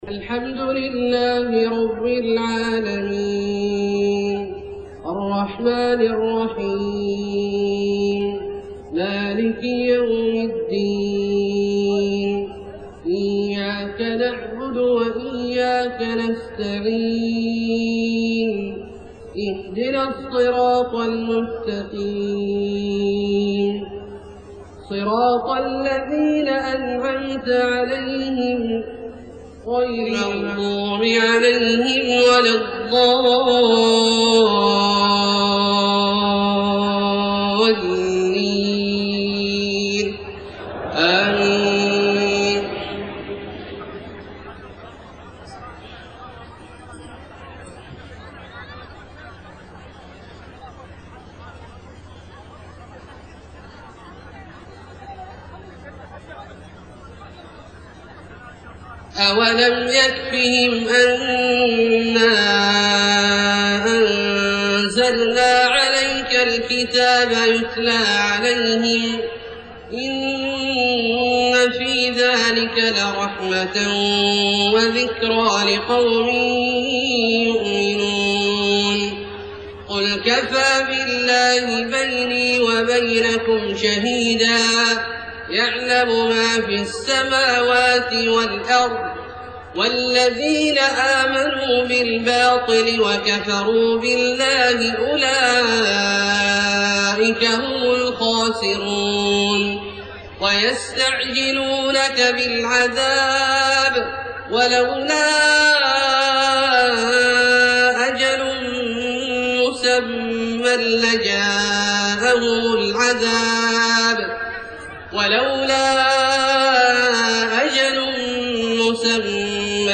صلاة العشاء 4-8-1431 من سورة العنكبوت {51-69} > ١٤٣١ هـ > الفروض - تلاوات عبدالله الجهني